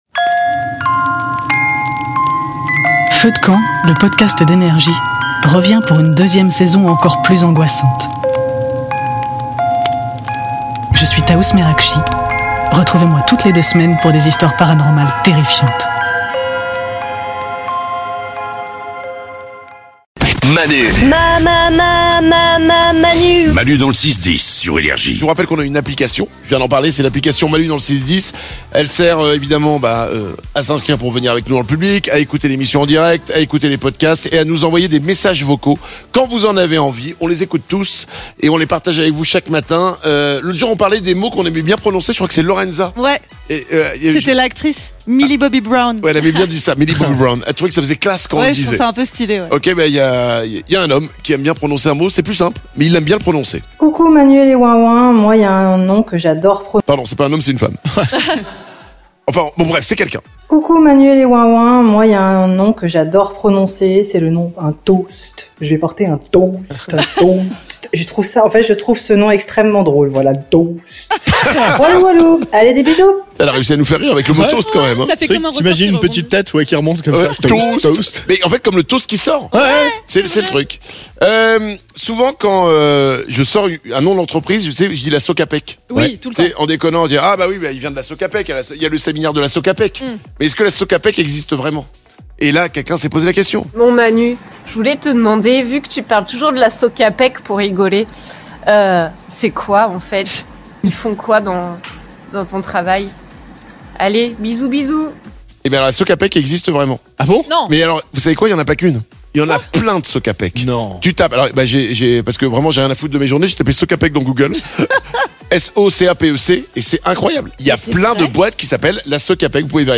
Tous les jours, on écoute les messages vocaux que vous nous envoyez sur notre application.